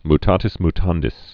(m-tätĭs m-tändĭs)